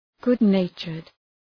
Shkrimi fonetik {,gʋd’neıtʃərd}